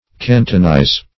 Can"ton*ize